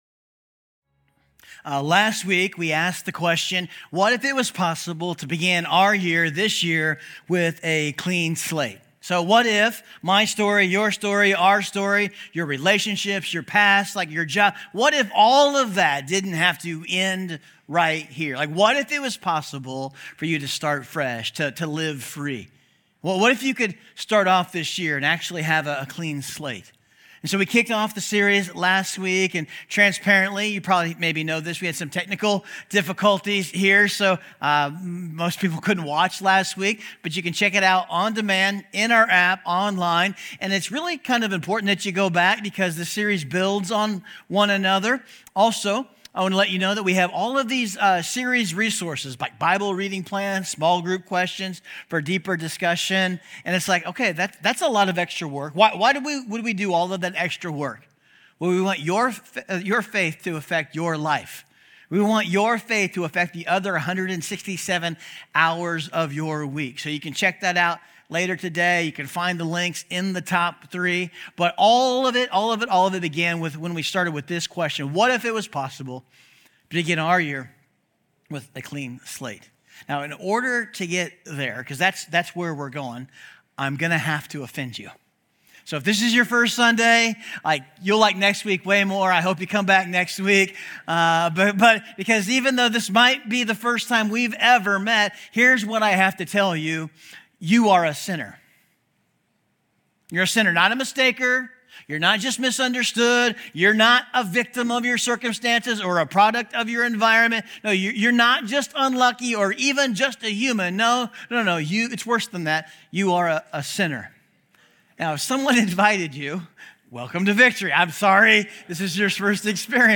Clean_Slate_Week_02_Message_Audio.mp3